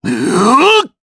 Shakmeh-Vox_Attack4_jp.wav